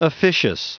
Prononciation du mot officious en anglais (fichier audio)
Prononciation du mot : officious